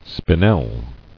[spi·nel]